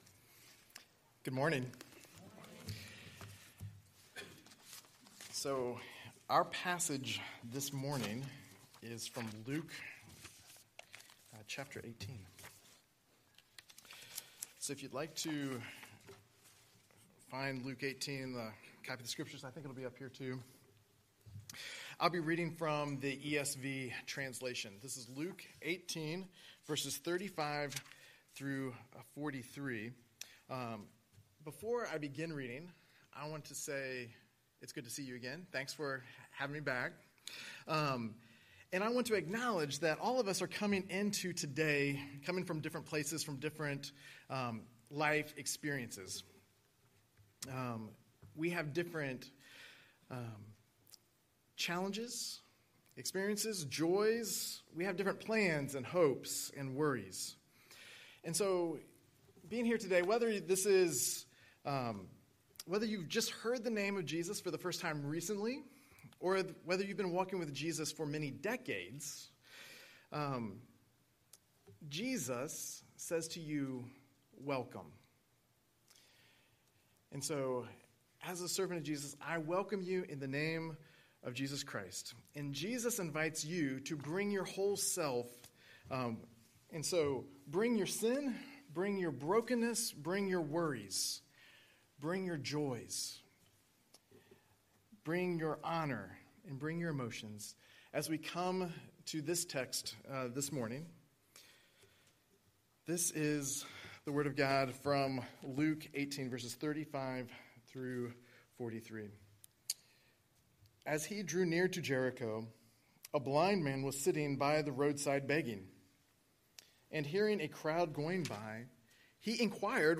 Scripture: Luke 18:35–43 Series: Sunday Sermon